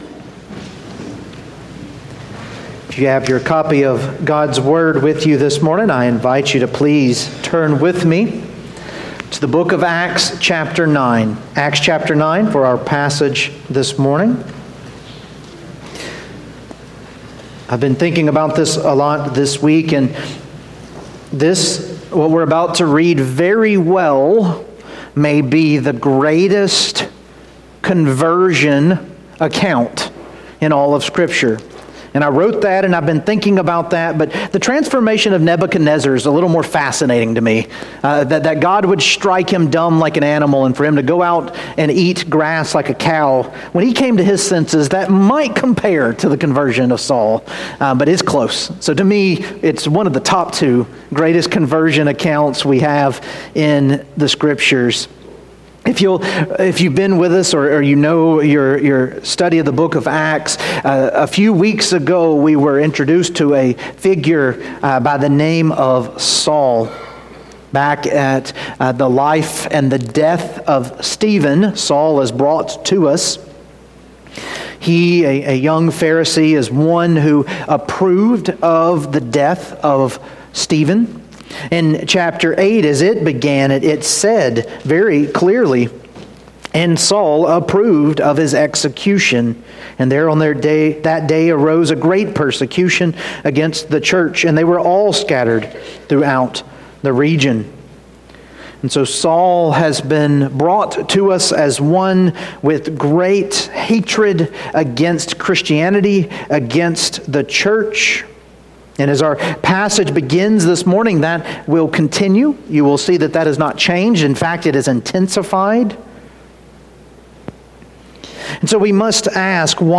Sermons
Sermons from Main Street Presbyterian Church: Columbus, MS